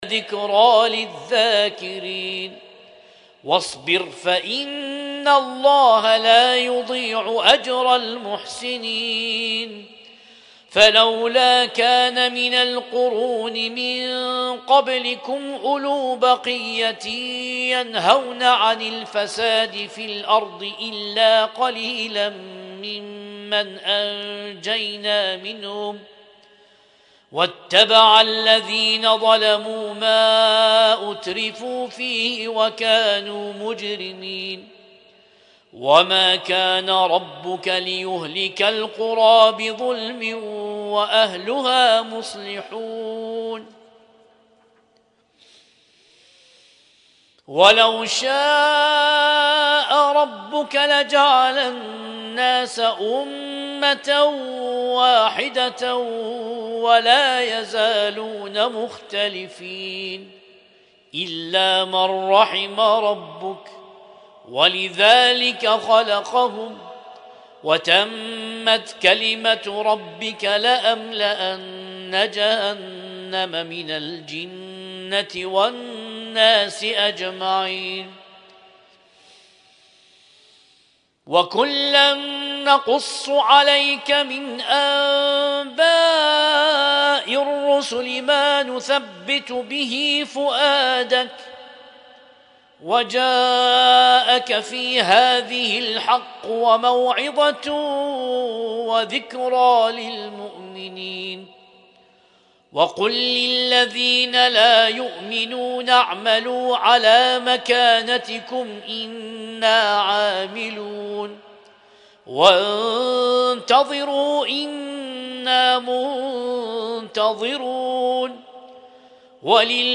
ليلة 12 من شهر رمضان المبارك 1447